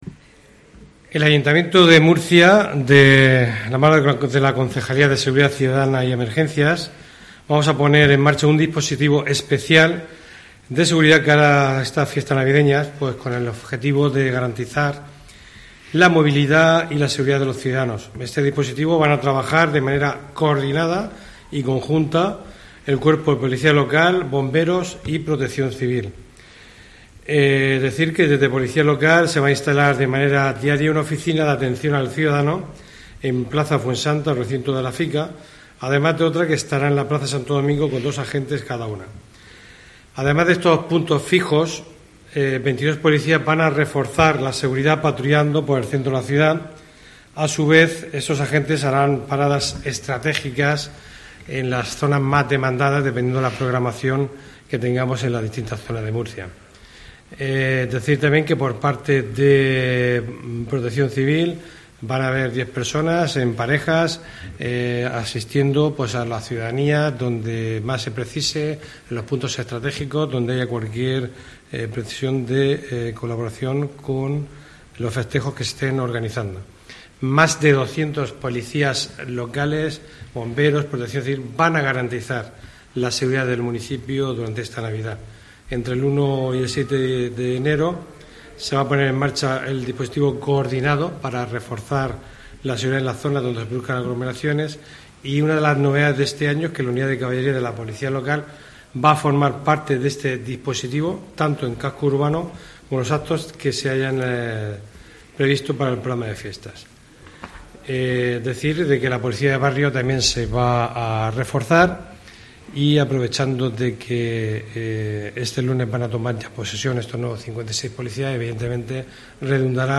Fulgencio Perona, concejal de Seguridad Ciudadana y Emergencias